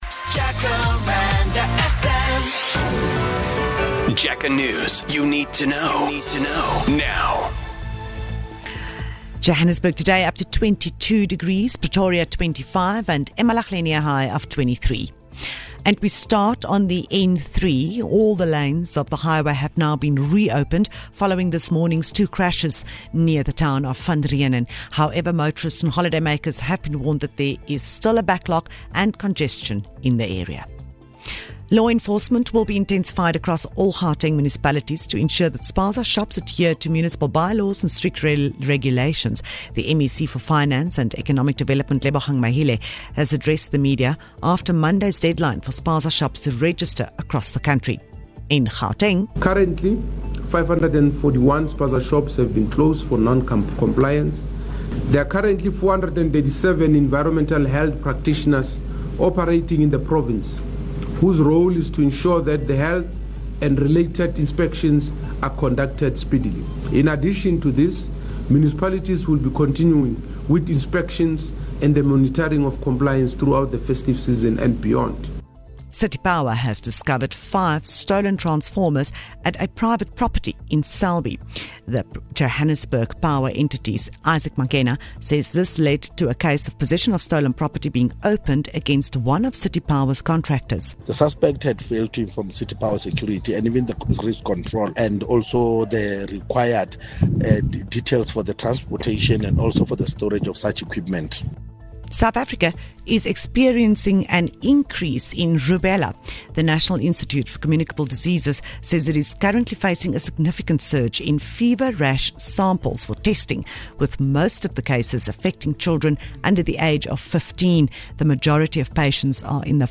10am News (English)